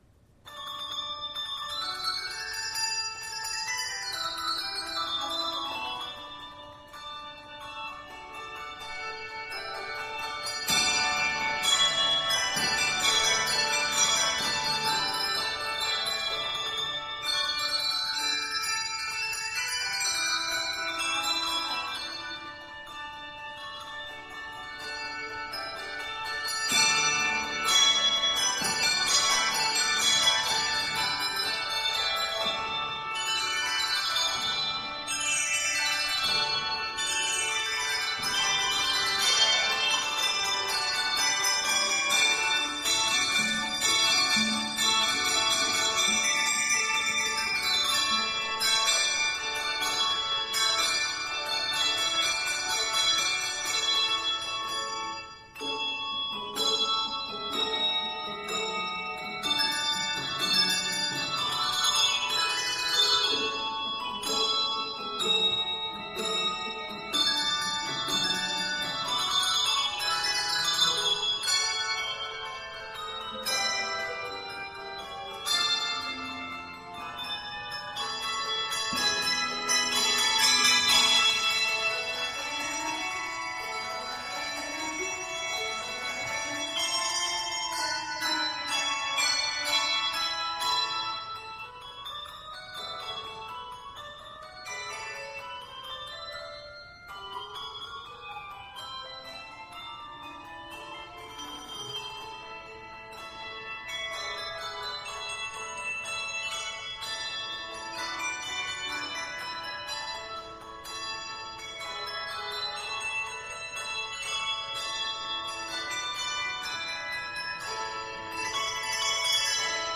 Ringers should enjoy this one, all 295 measures!